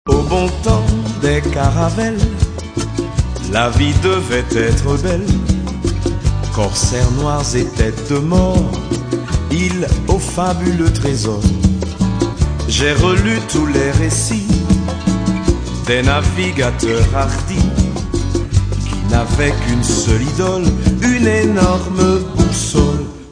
Batterie
Contrebasse